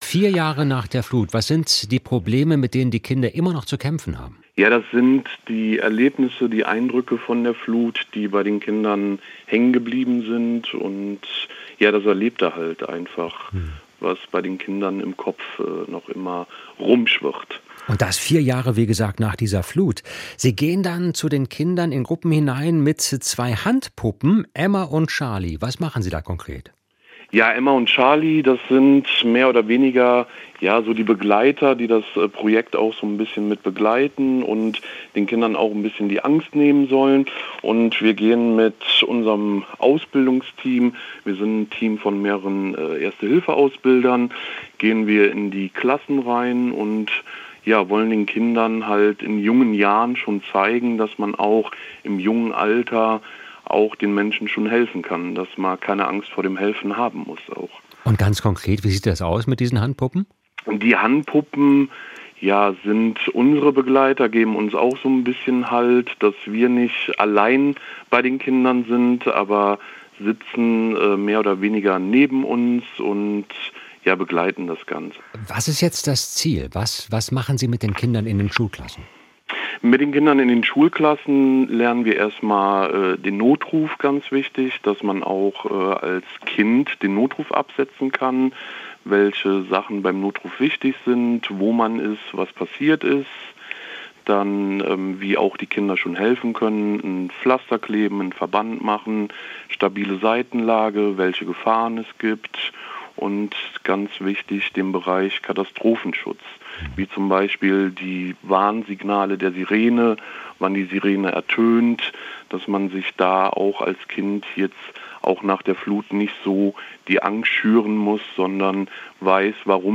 Mehr Interviews